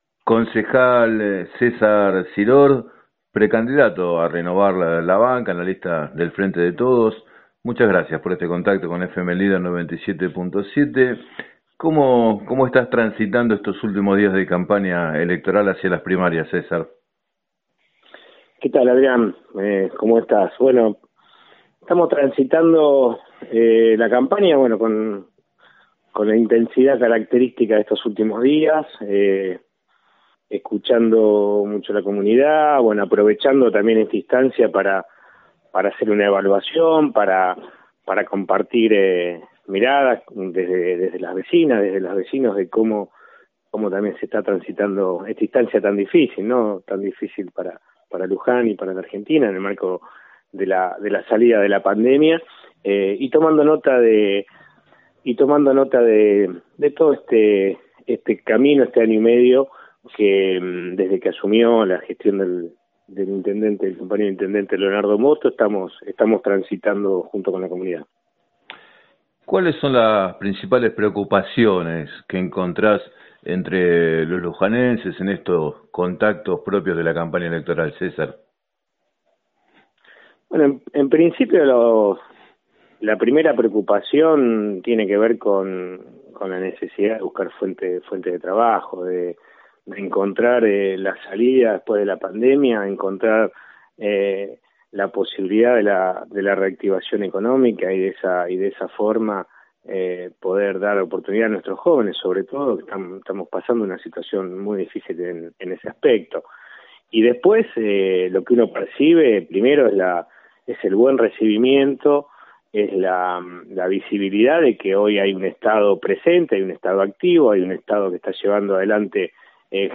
En declaraciones al programa “7 a 9” de FM Líder 97.7, Siror indicó que intentarán alcanzar la mayoría en el Concejo para “profundizar las políticas de obra pública, de salud, de viviendas y de reactivación de las Pequeñas y Medianas Empresas”.